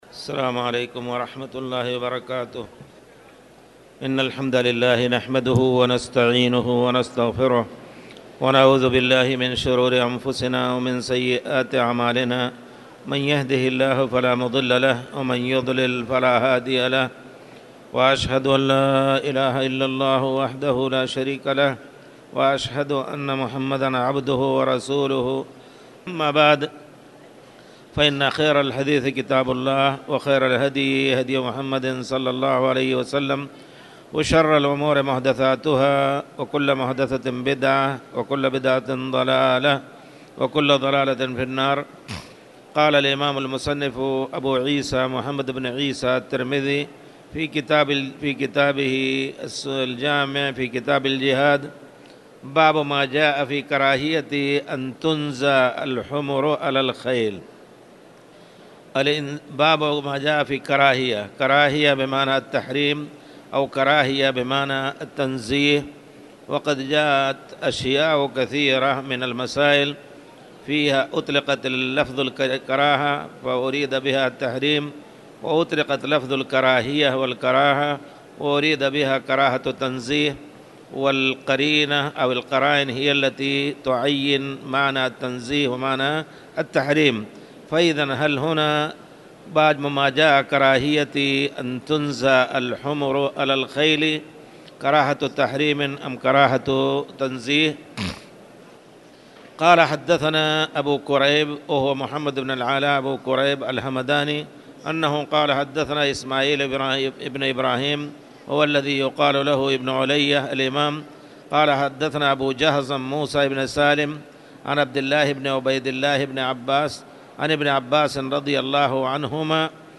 تاريخ النشر ١٤ شعبان ١٤٣٨ هـ المكان: المسجد الحرام الشيخ